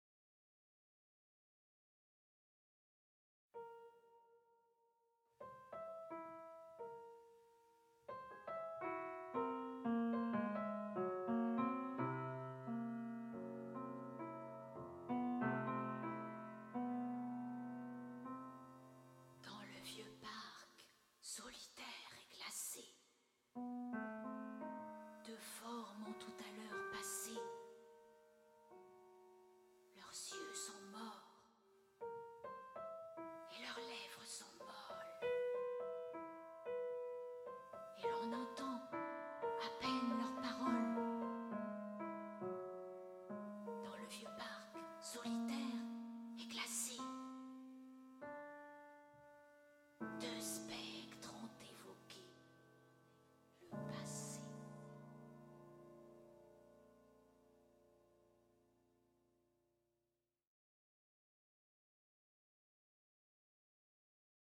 La mélodie française prend un parfum surnaturel, quelques gouttes d'humour noir versées dans une coupe, un philtre délicieux à partager avec le public.
piano